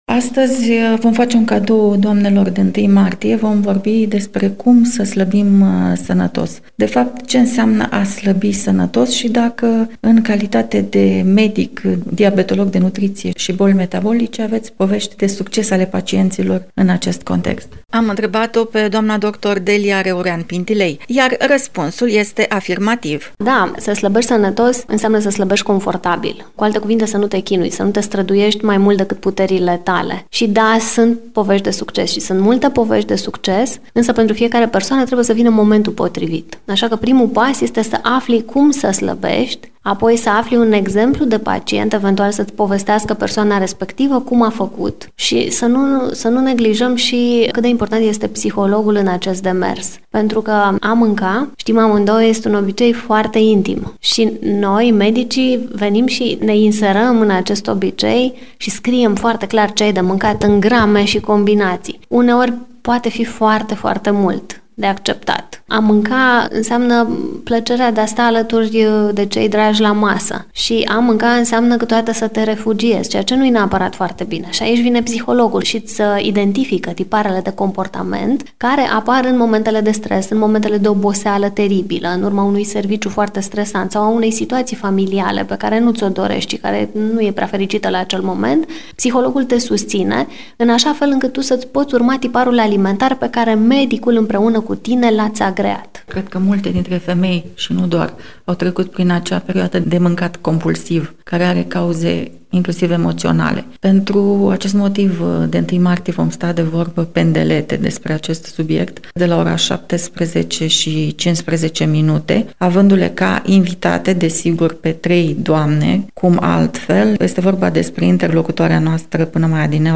1-martie-PROMO-IMPERATIV-1.mp3